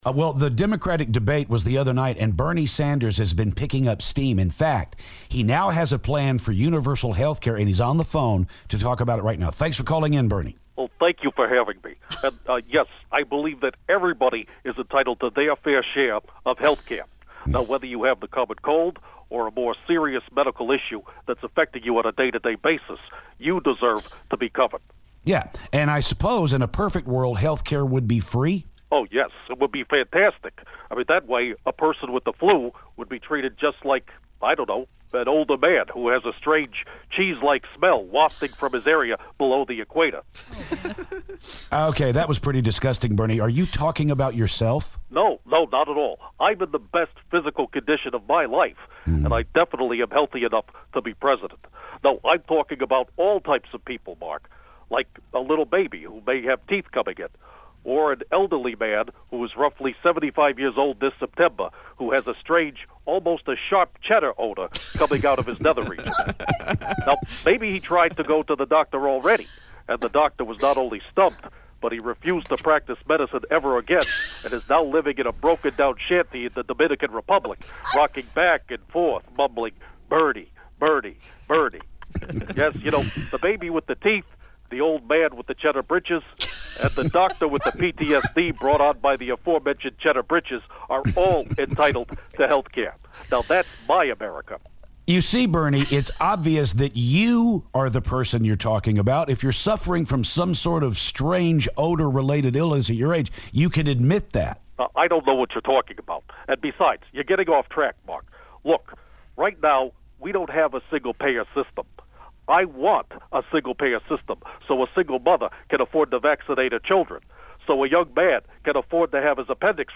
Presidential candidate Bernie Sanders calls to talk about Health Care,